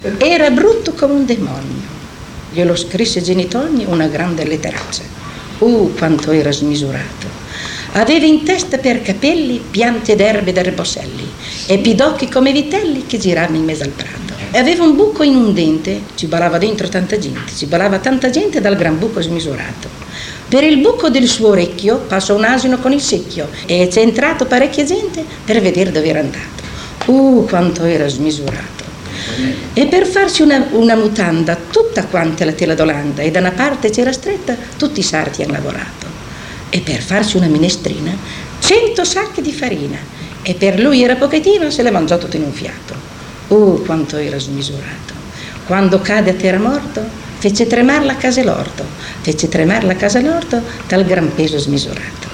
filastrocca - marcantonio.mp3